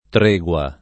tr%gUa o tr$gUa] (ant. triegua [trL$gUa]) s. f. — certam. aperta l’-e- nell’ant. triegua; concordi per l’aperta le attestaz. tosc. del ’600, pur dopo scomparso il dittongo -ie-; livellata poi la pronunzia (benché solo in un secondo tempo, teste C. Cittadini) alla chiusura già avvenuta nella terminaz. di adeguo, dileguo, seguo; quindi -e- chiusa preval. nelle registraz. dei dizionari — oggi in Tosc., più che una diversità da luogo a luogo, una diffusa incertezza tra le due pronunzie; e nelle altre regioni, cominciando da Roma e dall’It. mediana, una preferenza più o meno netta per l’aperta — non risolutive le ragioni dell’etimologia (che muove da diverse lingue germaniche con diverse forme), e superate dalla distribuz. delle pronunzie nel tempo e nello spazio — cfr. stregua